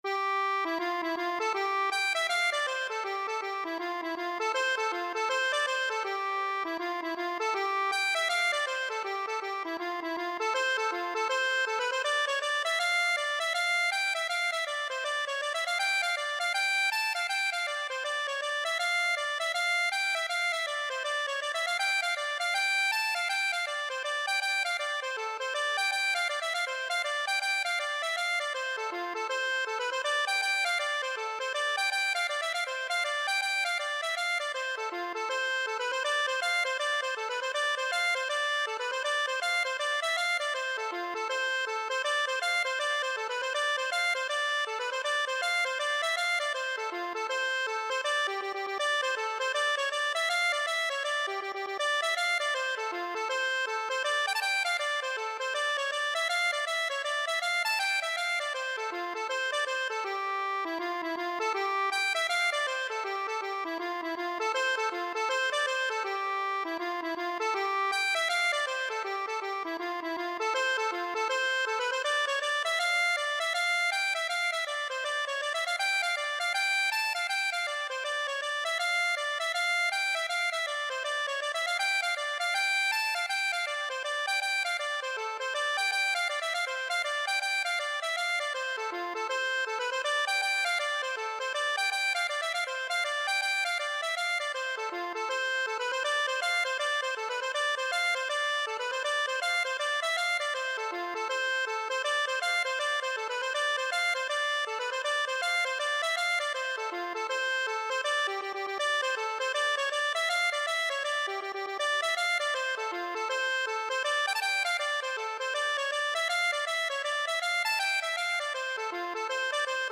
Free Sheet music for Accordion
4/4 (View more 4/4 Music)
E5-A6
C major (Sounding Pitch) (View more C major Music for Accordion )
Accordion  (View more Easy Accordion Music)
Traditional (View more Traditional Accordion Music)